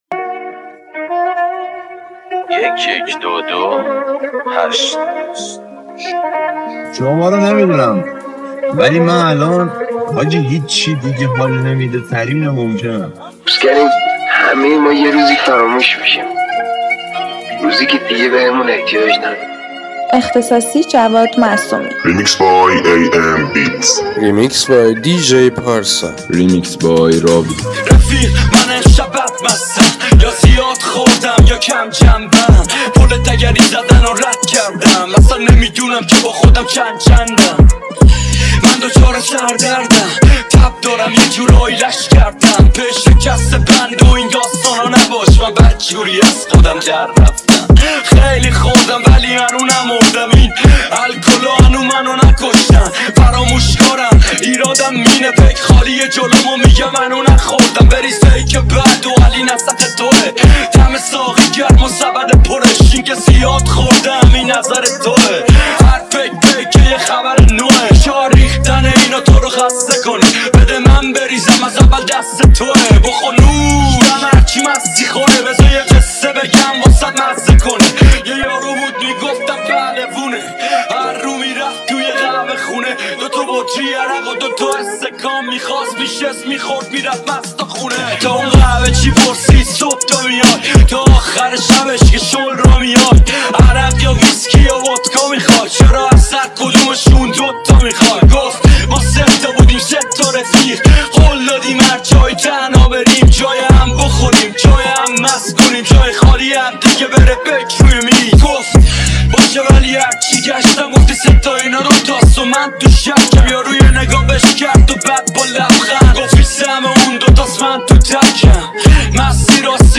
ریمیکس تریبال